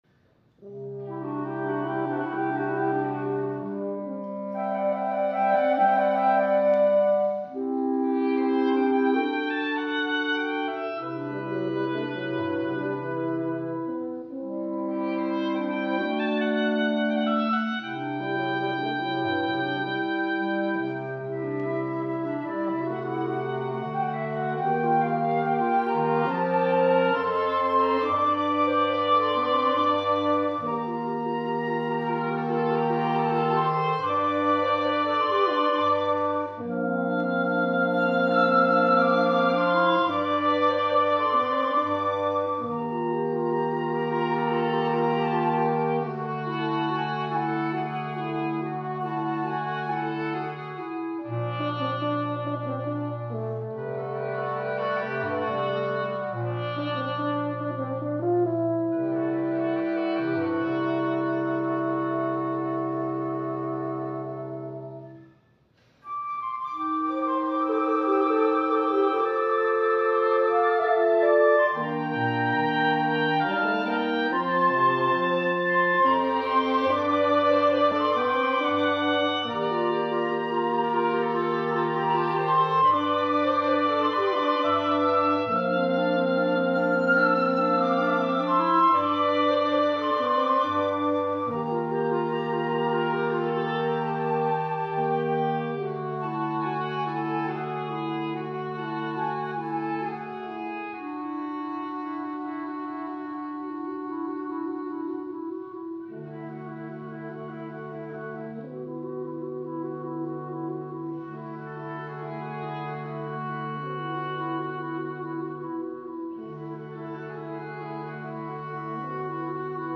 Voicing: Woodwind Quintet